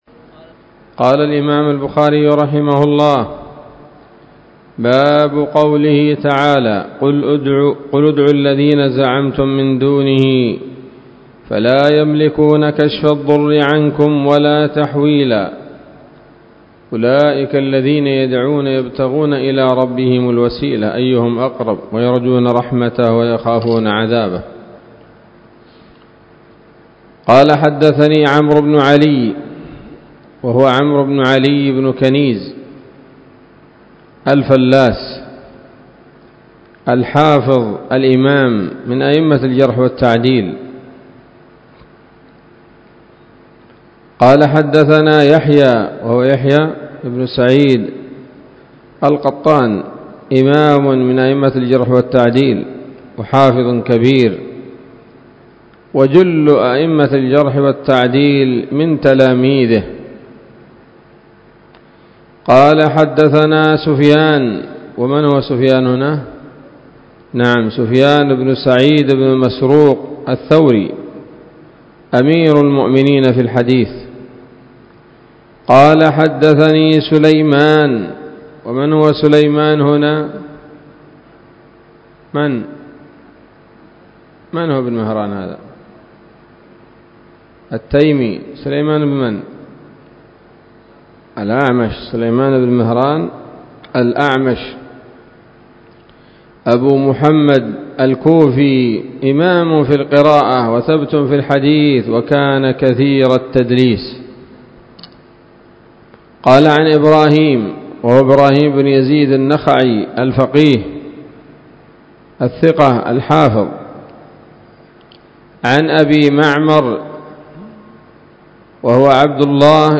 الدرس السادس والخمسون بعد المائة من كتاب التفسير من صحيح الإمام البخاري